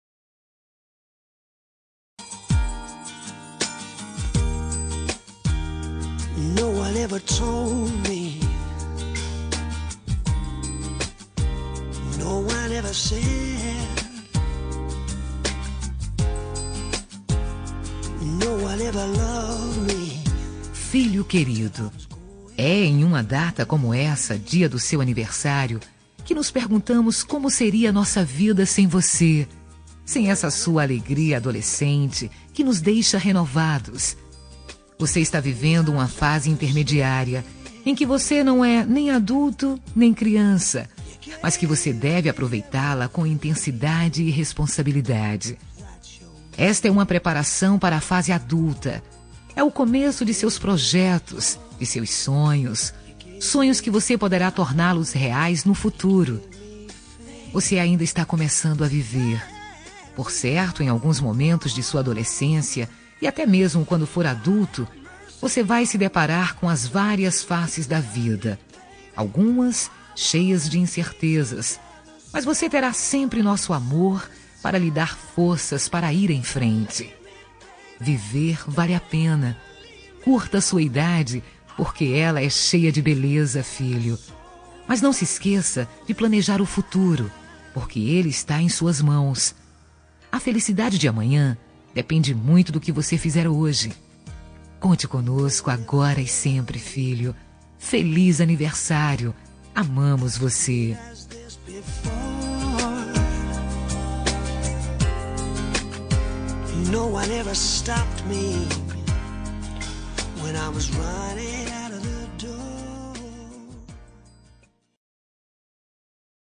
Aniversário de Filho – Voz Feminino – Cód: 5212 – Plural